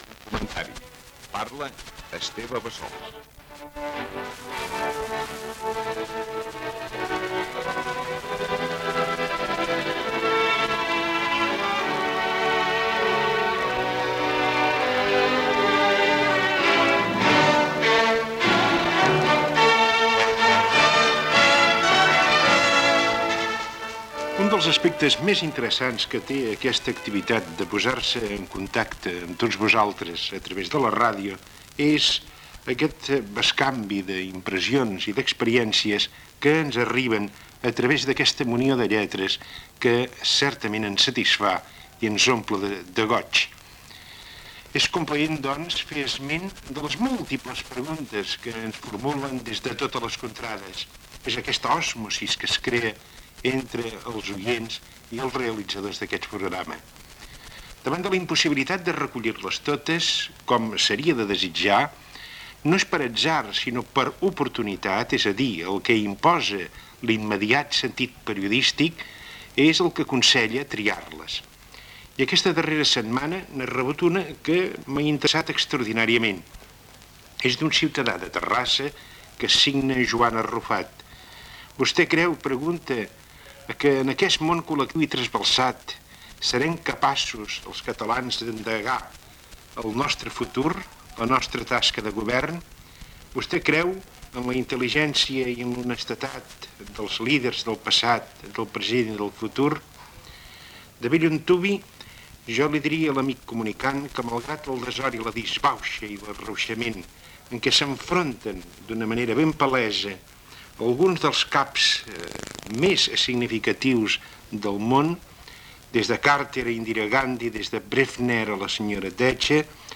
Cultura
Presentador/a
FM